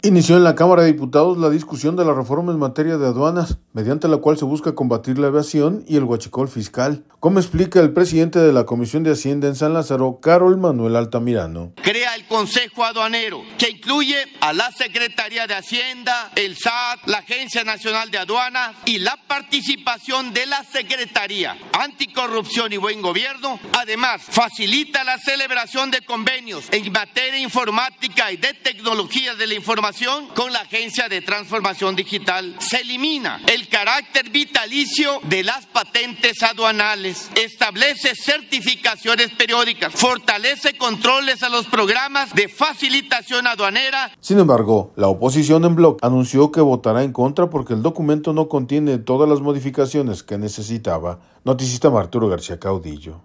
Inició en la Cámara de Diputados la discusión de la reforma en materia de aduanas, mediante la cual se busca combatir la evasión y el huachicol fiscal, como explica el presidente de la Comisión de Hacienda en San Lázaro, Carol Manuel Altamirano.